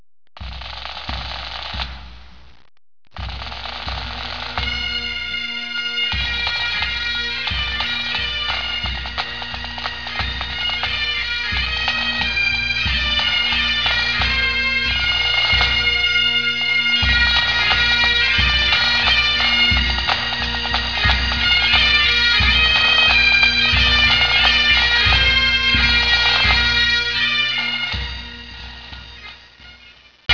PIPE BAND MUSIC